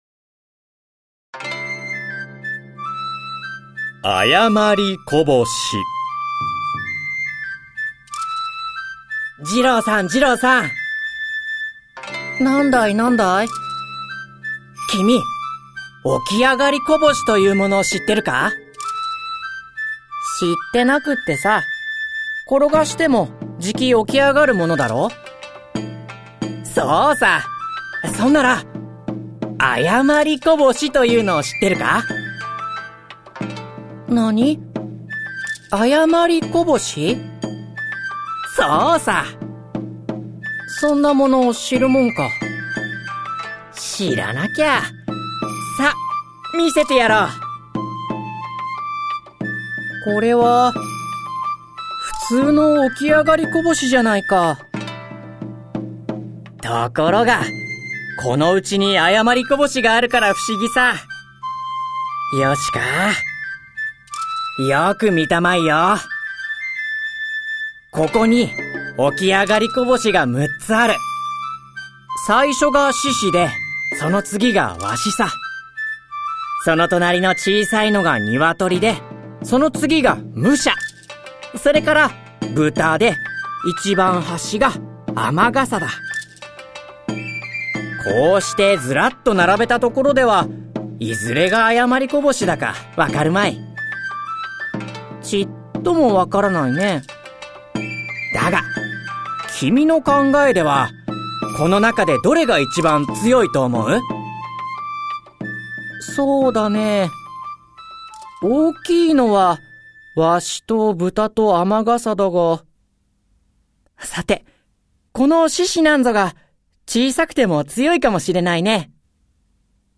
子どもの想像力を豊かに育む 読み聞かせオーディオブック
プロとして活躍する朗読家や声優、ナレーター達が感情豊かに読み上げます。